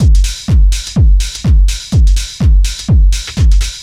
NRG 4 On The Floor 035.wav